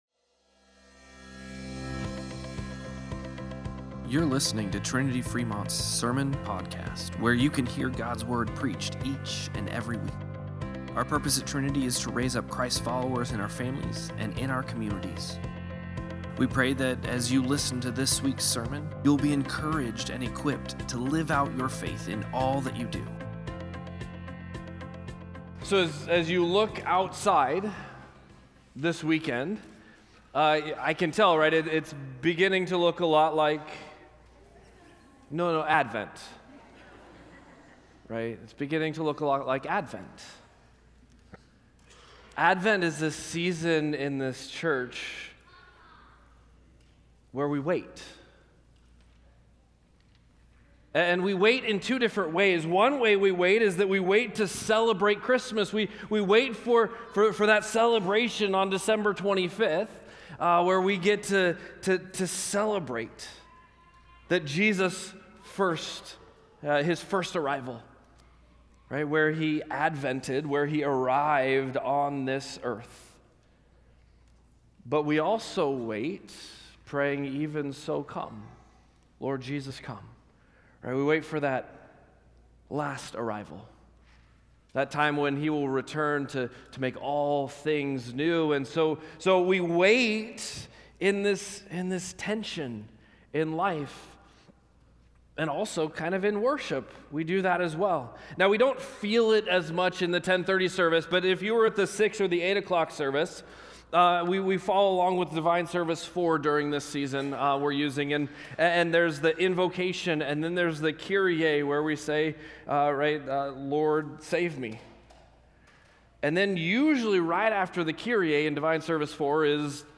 Sermon-Podcast-11-30.mp3